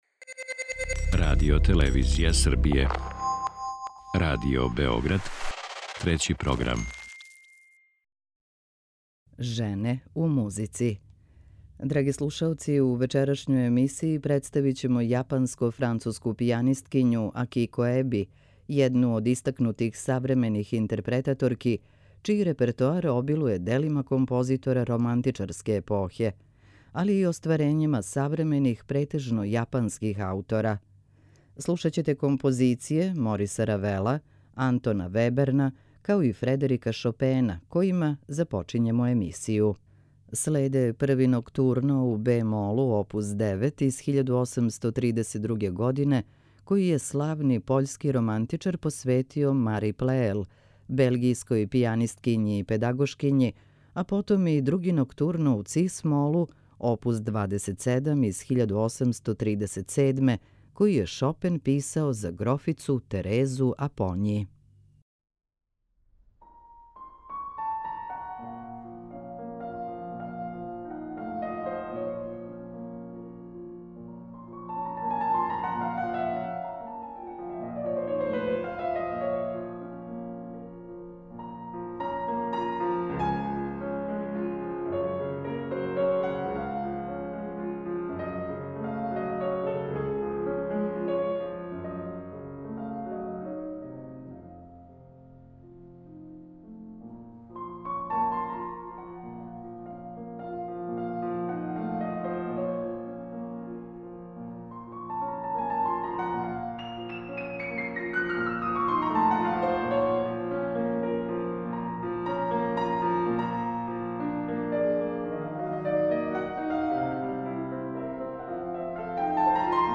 Представићемо јапанско-француску пијанисткињу Акико Еби, једну од истакнутих савремених интерпретаторки чији репертоар обилује делима композитора романтичарске епохе, али и остварењима савремених, претежно јапанских аутора. Слушаћете композиције Фредерика Шопена (Ноктурна у бе молу, број 1 опус 9 и у цис молу, број 2 опус 27), Мориса Равела (циклус Гаспар ноћи) и Антона Веберна (Квинтет за гудаче и клавир).